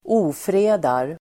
Uttal: [²'o:fre:dar]